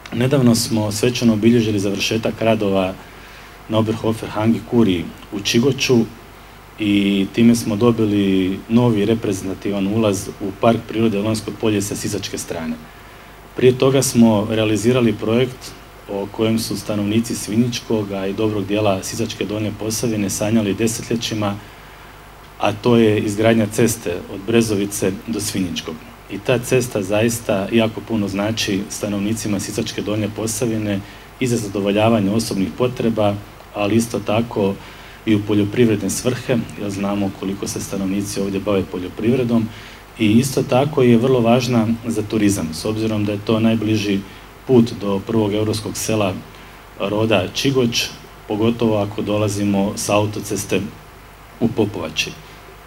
Obnovljenu zgradu otvorio je župan Ivan Celjak koji je u svome obraćanju istaknuo važnost podrške razvoju ruralnih područja i očuvanja kulturne baštine ovoga kraja.